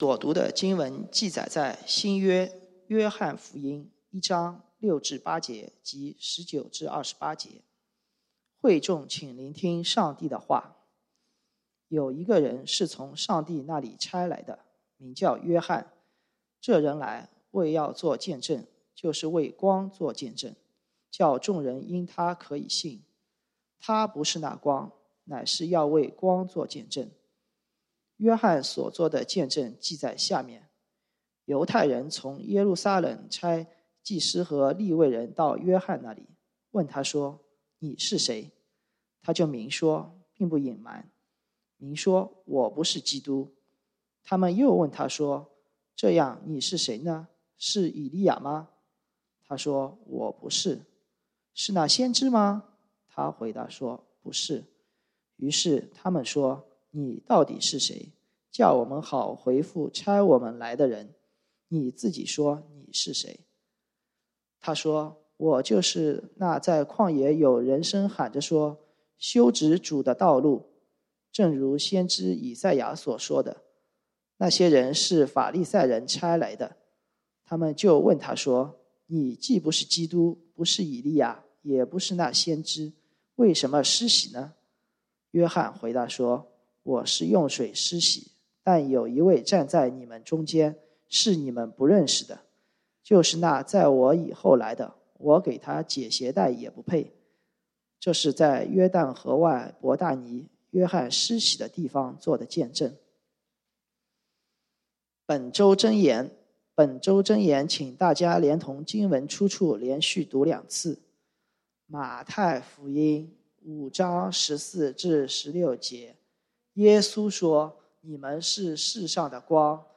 講道經文：《約翰福音》John 1:6-8. 19-28 本週箴言：《馬太福音》Matthew 5:14-16 耶穌說： 「你們是世上的光。